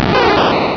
pokeemerald / sound / direct_sound_samples / cries / bulbasaur.aif